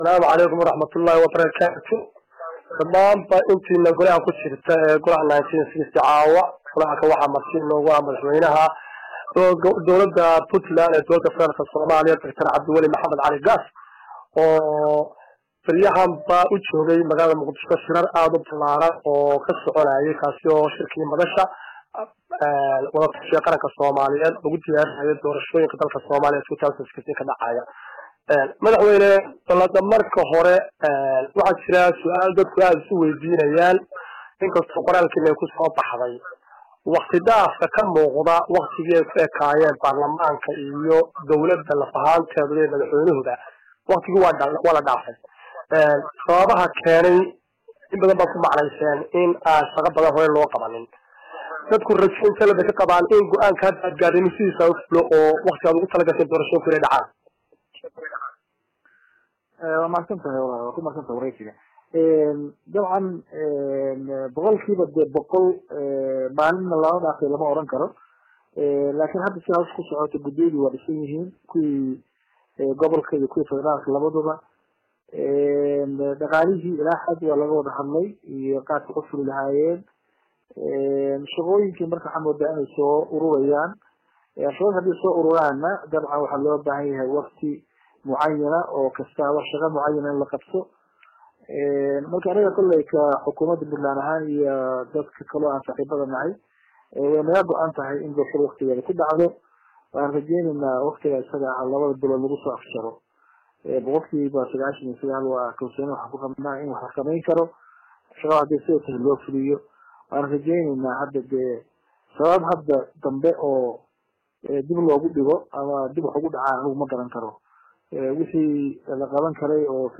Dhagayso: Madaxweyne Gaas oo waraysi dhinacyo badan taabanaya siiyey Golaha 1960
Dhagayso waraysiga madaxweyne Gaas ay Golaha 1960la yeelatay